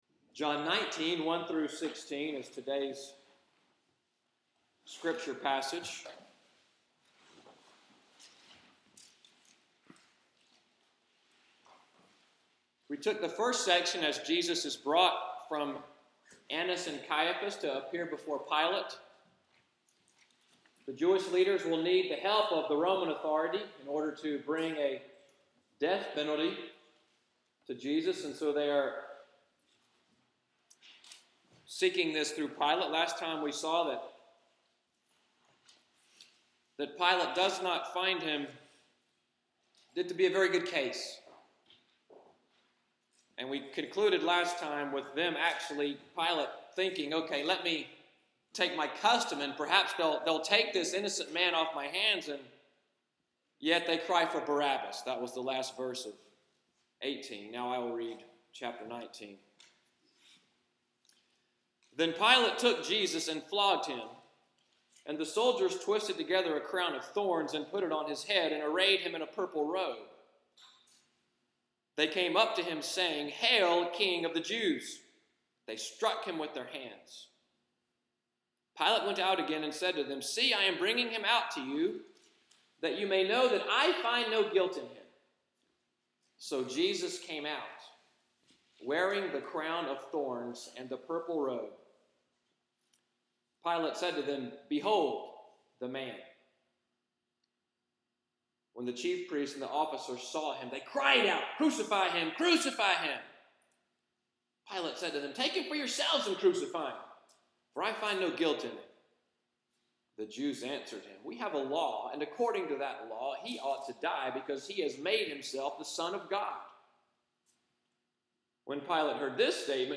Audio from the sermon: “Pilate On Trial” Part 2: Rejecting the Kingdom of God, March 16, 2014